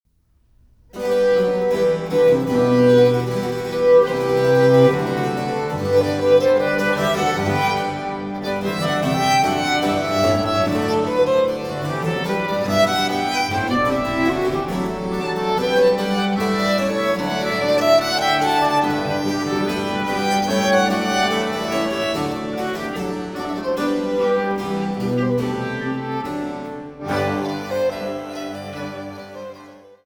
durch Flöten, Hackbrett und diverse Continuo-Instrumente